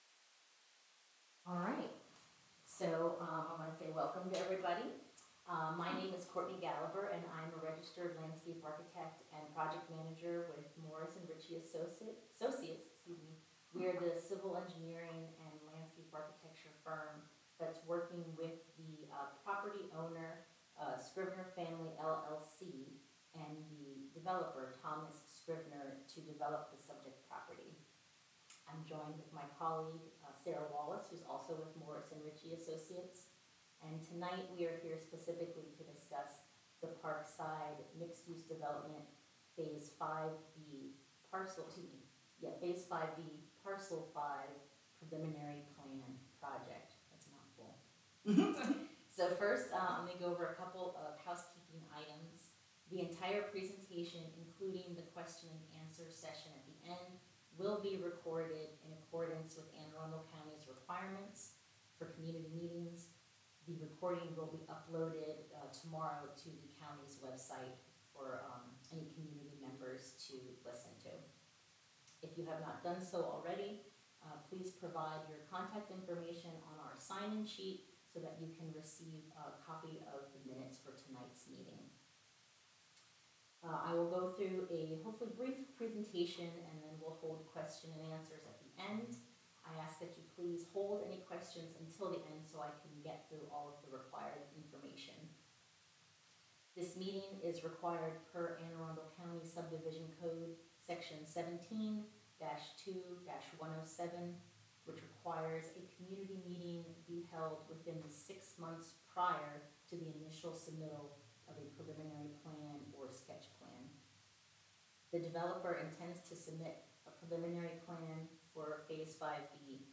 Preliminary Plan Pre-submittal Meeting